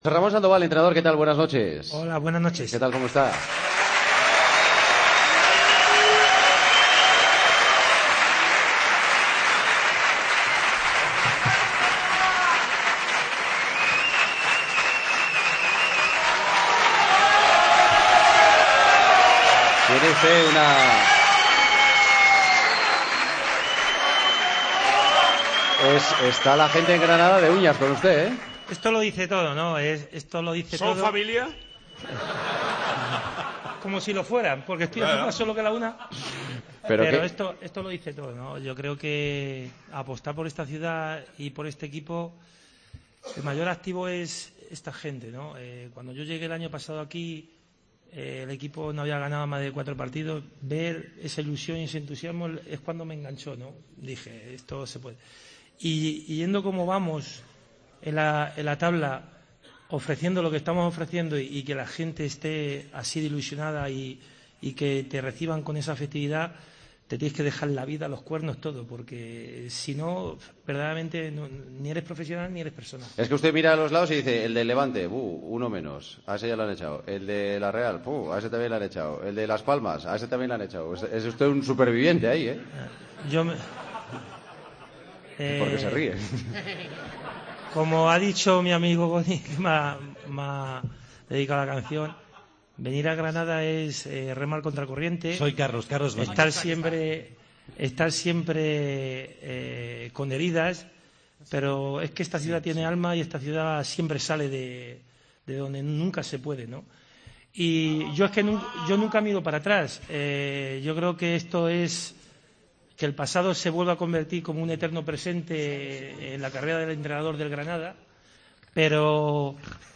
AUDIO: El entrenador del Granada y el jugador granadino del Nápoles, invitados especiales en El Partido de las 12 de este jueves desde Granada.